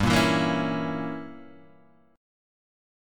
G Diminished 7th